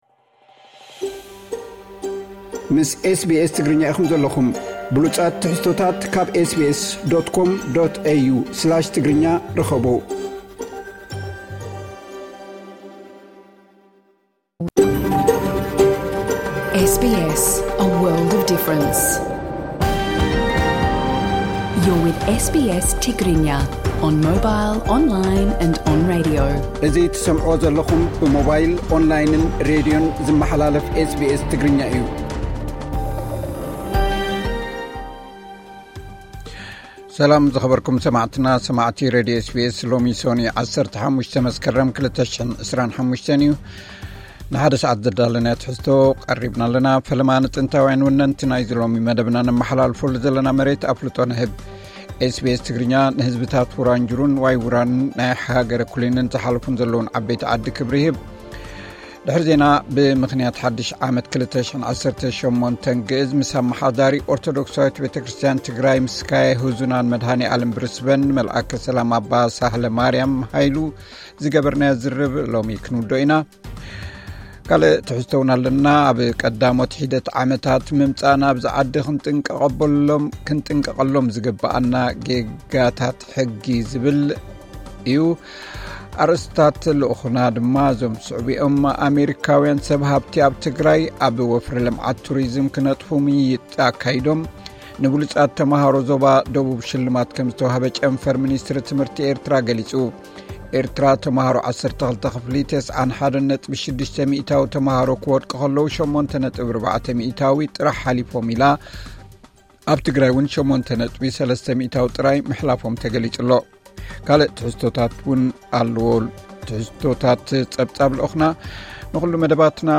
ቐጥታ ምሉእ ትሕዝቶ ኤስ ቢ ኤስ ትግርኛ (15 መስከረም 2025)